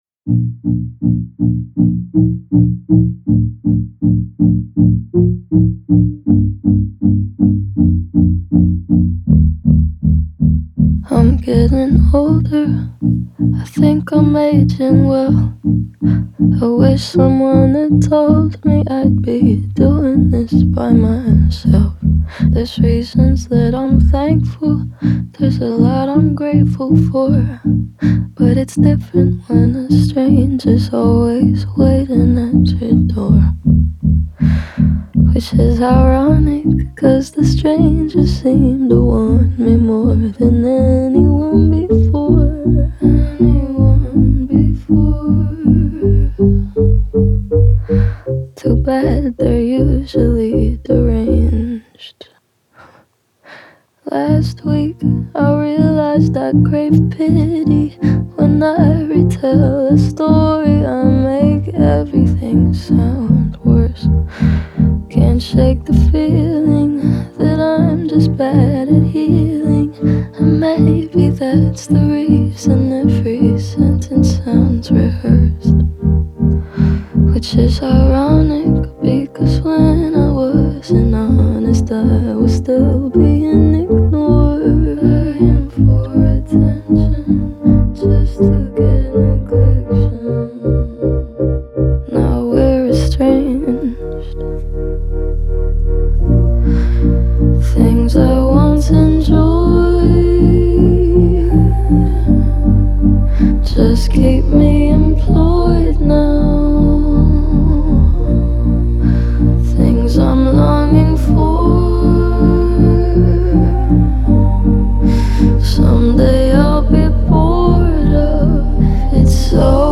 относящийся к жанру поп с элементами альтернативы.